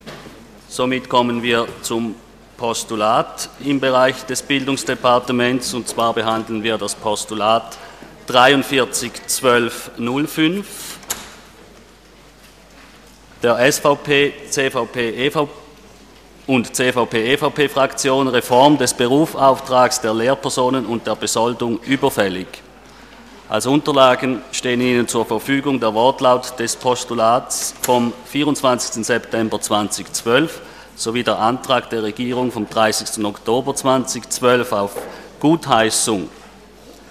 26.11.2012Wortmeldung
Session des Kantonsrates vom 26. bis 28. November 2012